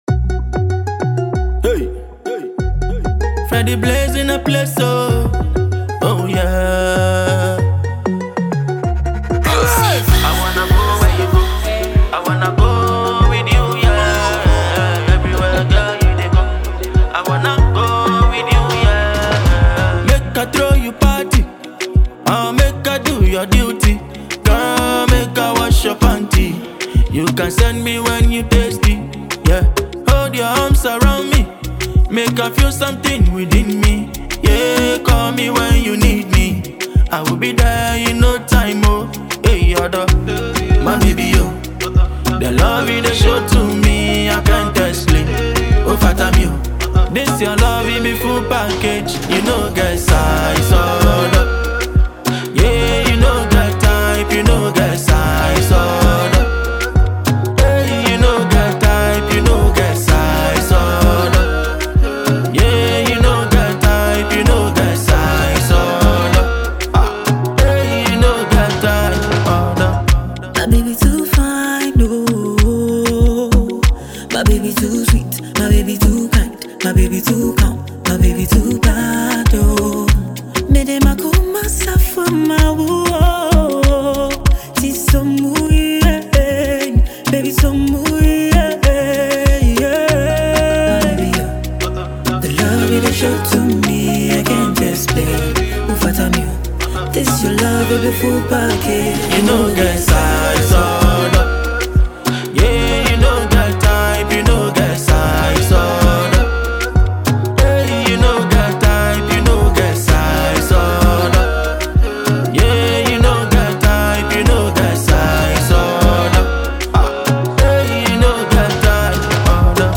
brings her trademark energy and sultry vocal finesse
crafts a vibrant, dance-ready instrumental